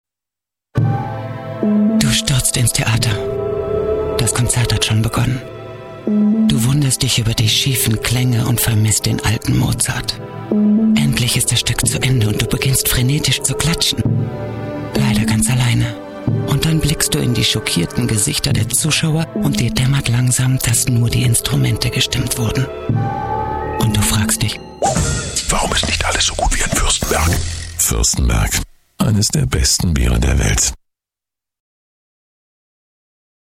fuerstenberg_werbung3.mp3